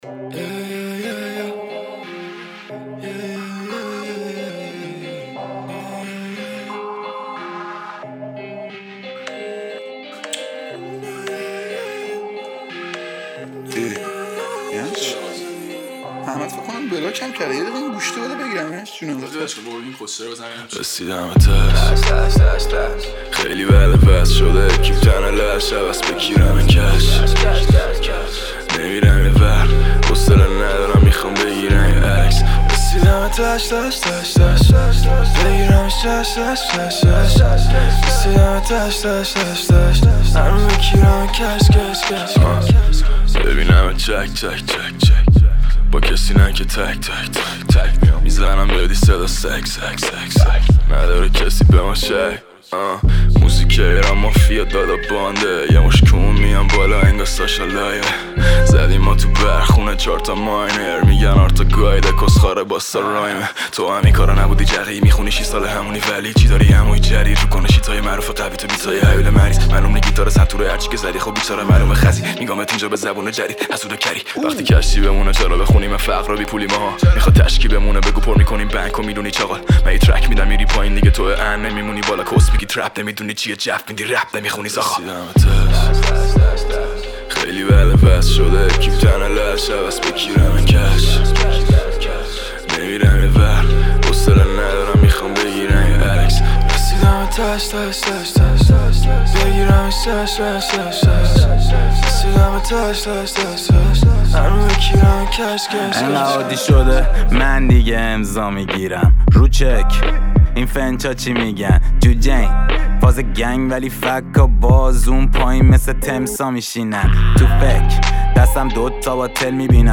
دسته بندی : موزیک رپ تاریخ : دوشنبه 29 اردیبهشت 1399